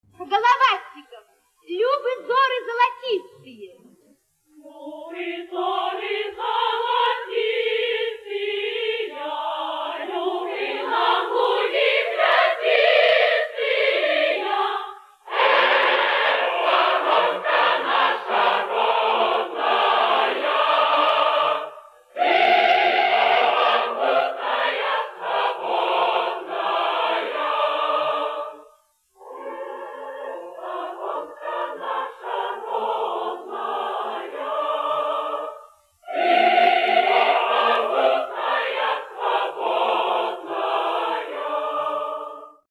Таму прапануем паслухаць узор вельмі характэрнай для таго часу беларускай музычнай прадукцыі — «Любы зоры залацістыя».